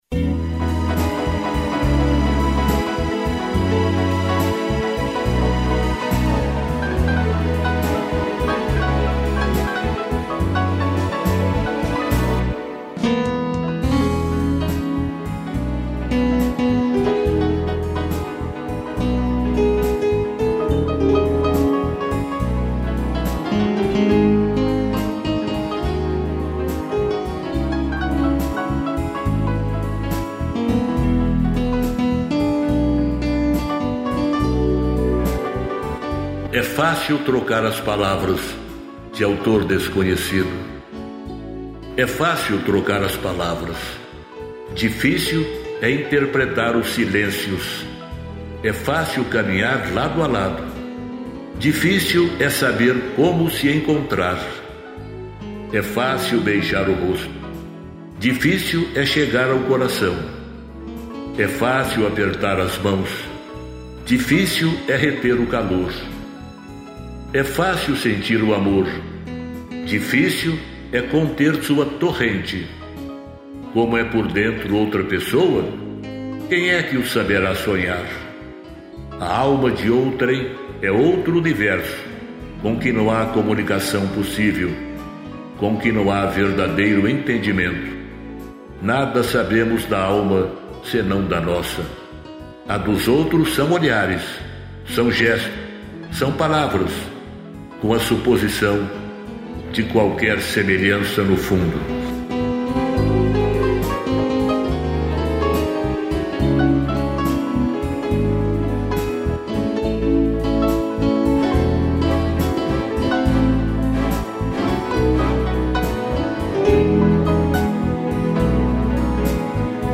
piano e flauta